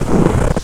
STEPS Snow, Walk 04-dithered.wav